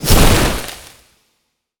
nature_spell_vines_blast_impact1.wav